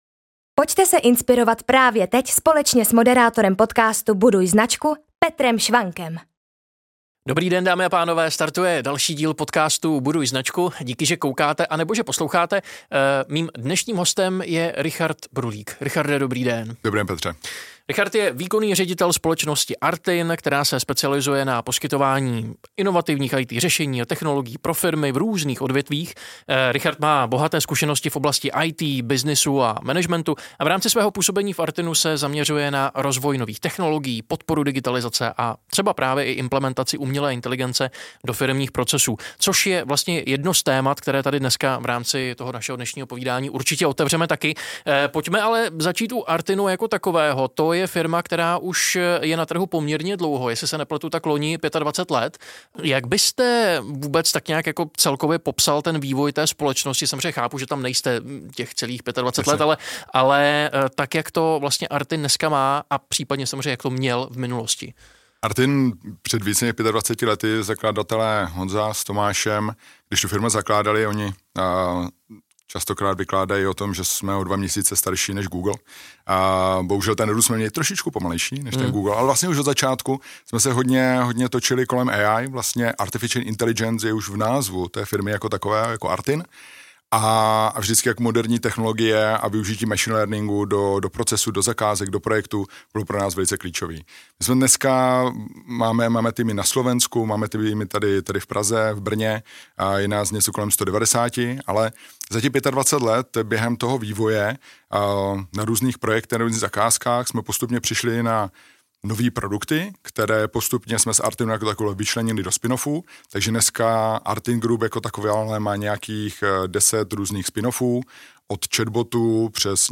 Tématem dnešního rozhovoru bude mimo jiné umělá inteligence, která je nakonec obsažena už od počátku v samotném názvu ARTIN.